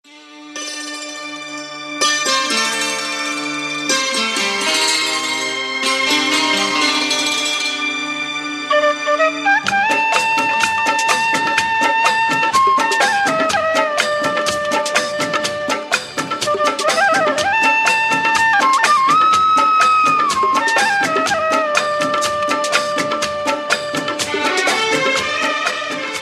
Enjoy high quality Bollywood sad romantic melody ringtone.